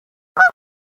loose goose ... honk honk
goose2.ogg